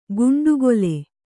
♪ guṇḍugole